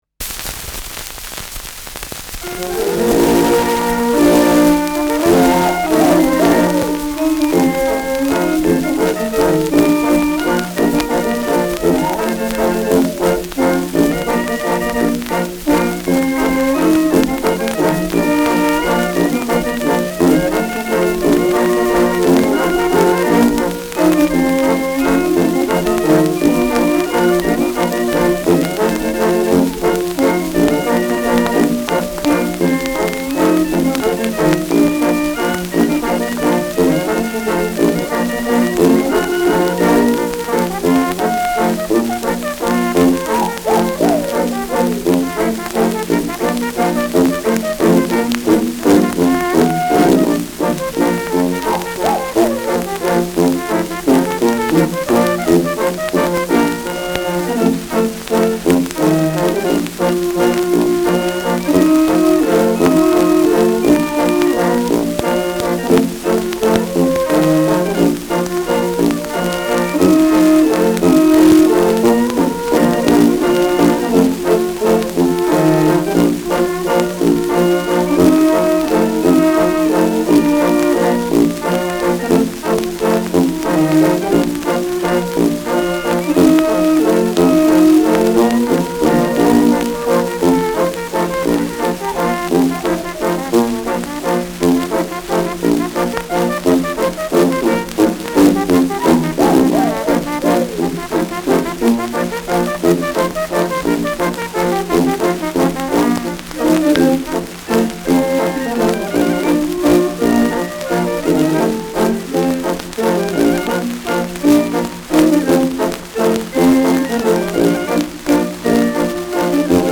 Schellackplatte
präsentes Rauschen : präsentes Knistern : abgespielt : leiert
Dachauer Bauernkapelle (Interpretation)
Mit Juchzer.